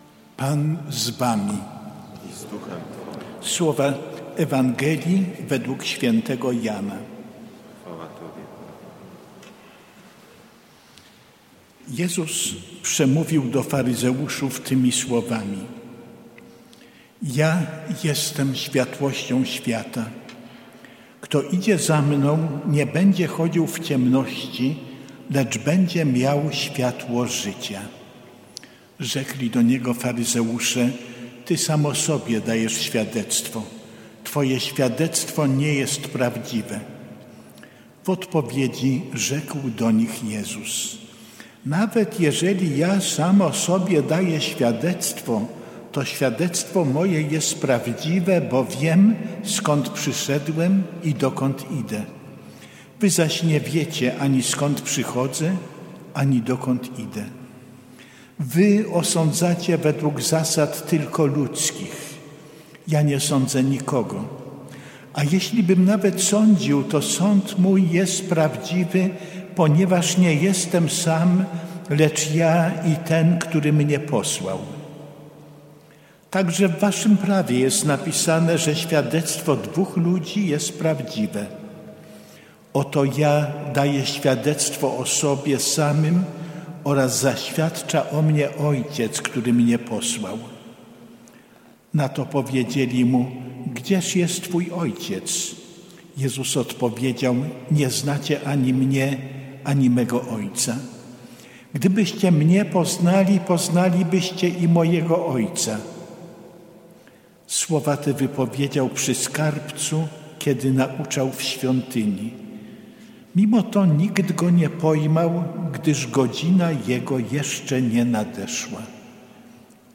W tym miejscu zamieścimy nagrania czterech dni rekolekcji, jakie prowadzi w Świątyni Opatrzności Bożej